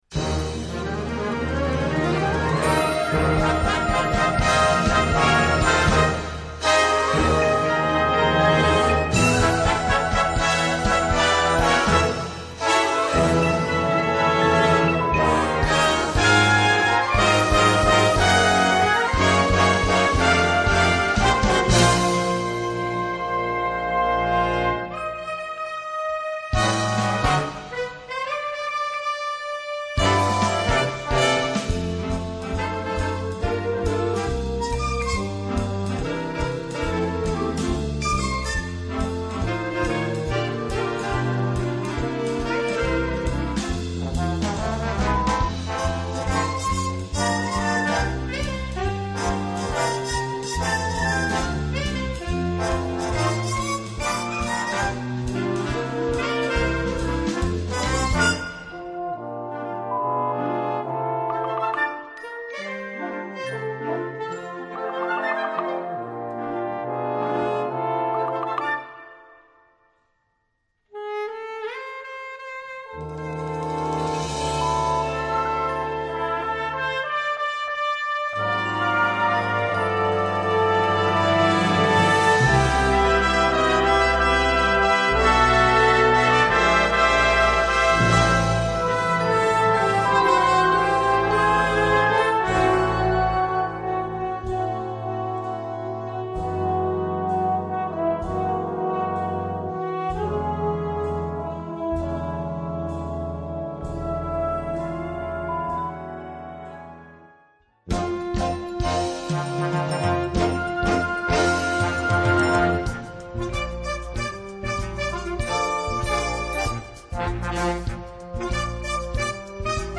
Gattung: Konzertantes Potpourri
Besetzung: Blasorchester